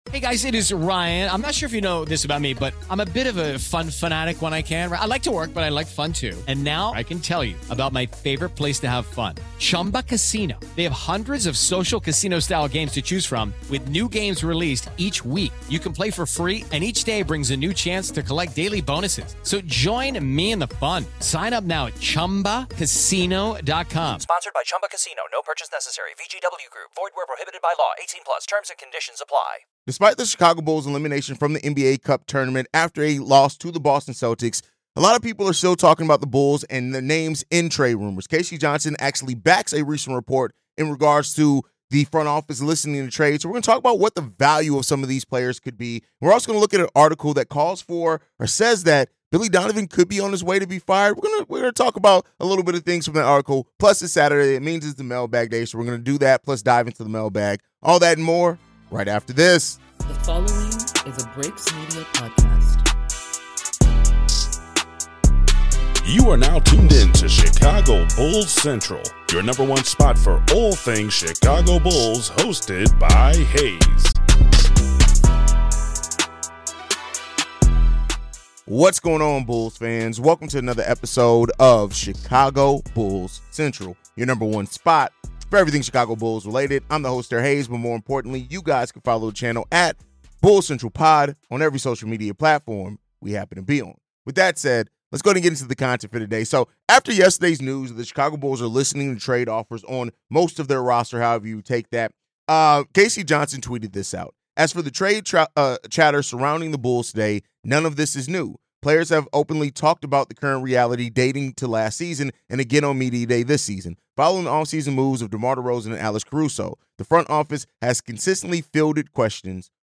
The episode also explores the possibility of head coach Billy Donovan's departure, as well as the team's ongoing struggles with defense and consistency. Listener voicemails add diverse perspectives on the Bulls' performance, coaching decisions, and potential roster changes.